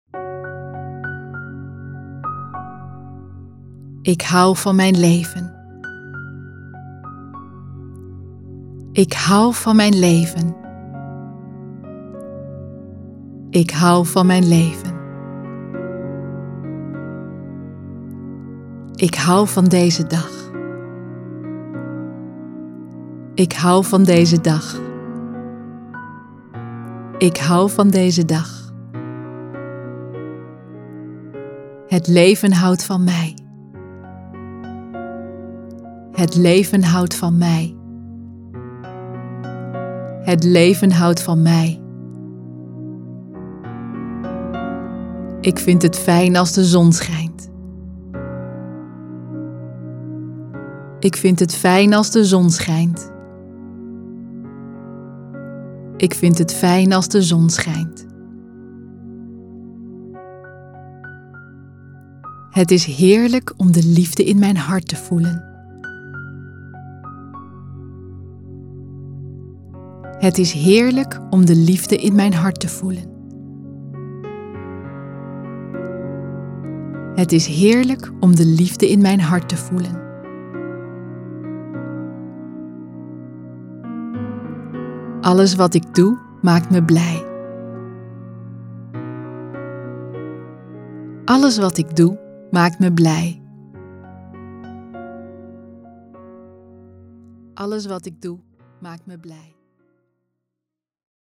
luisterboek
Affirmaties